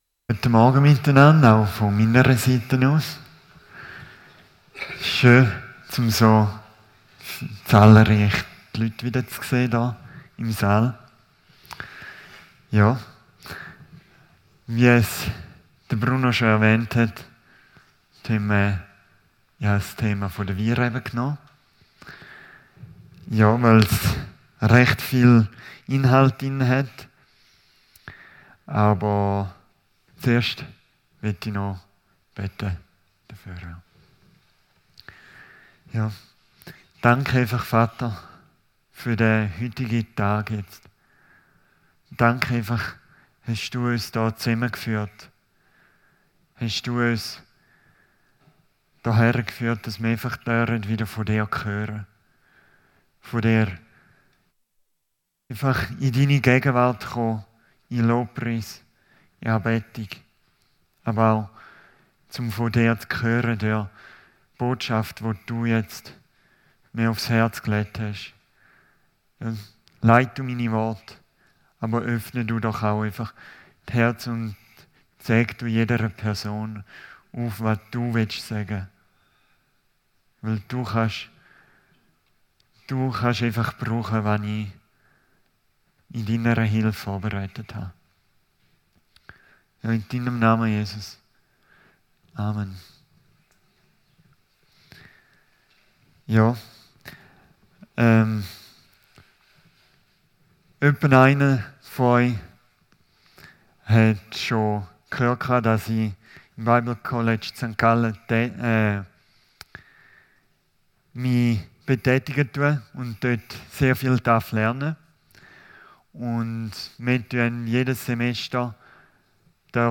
Predigt 4. Mai 2025